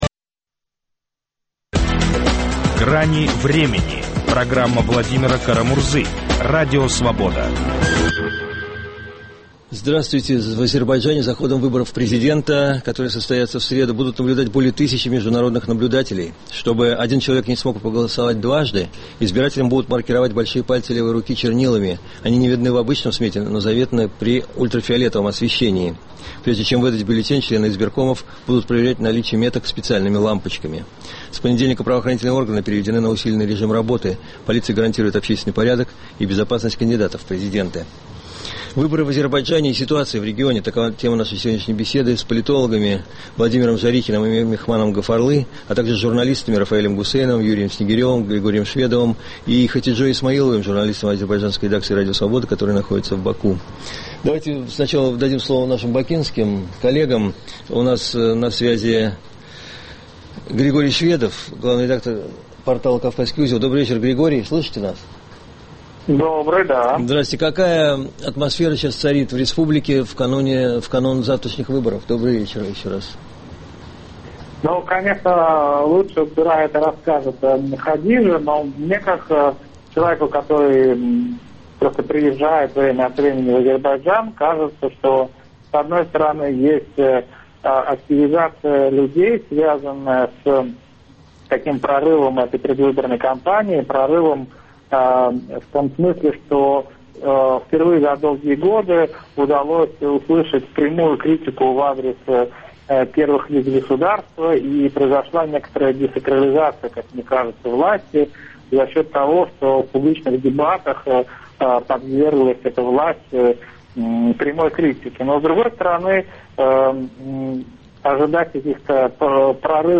Выборы в Азербайджане и ситуация в регионе. Об этом беседуем с политологами